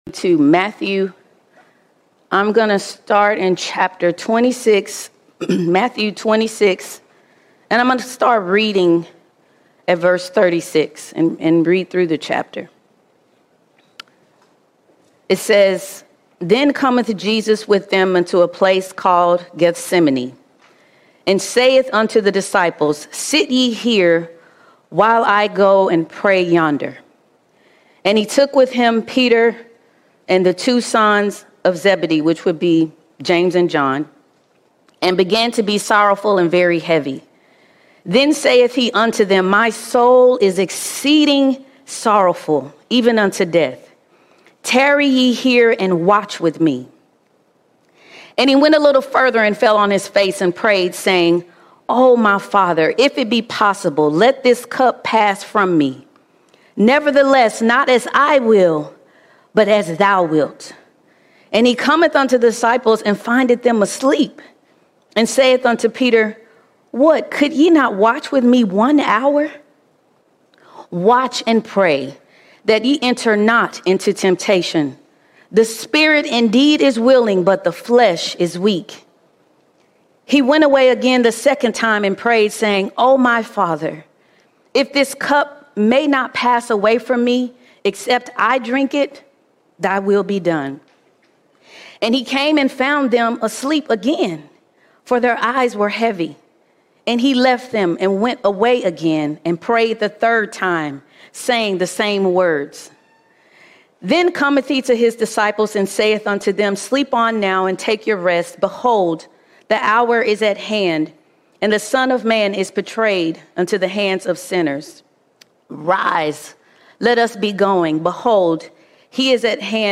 25 August 2025 Series: Sunday Sermons All Sermons The Watchman's Hour The Watchman’s Hour No matter what part of the Body we belong to, we are all called to be watchmen in this hour.